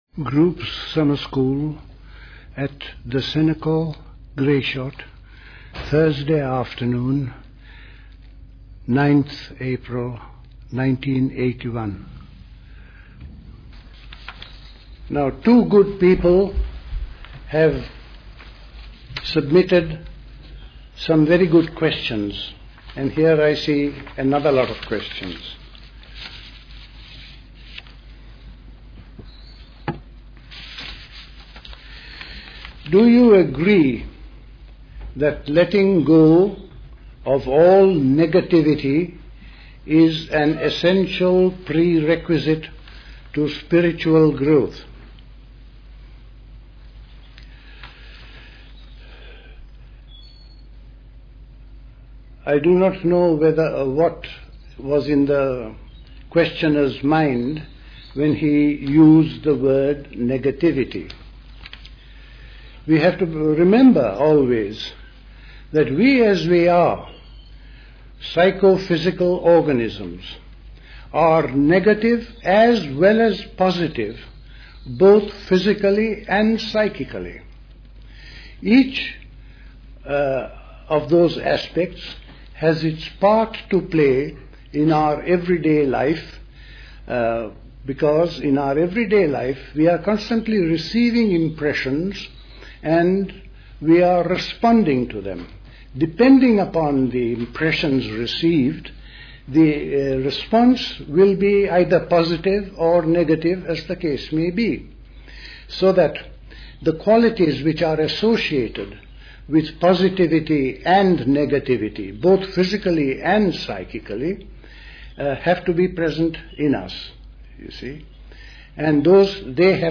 A talk
at the Convent of the Cenacle, Grayshott, Hampshire on 9 th April 1981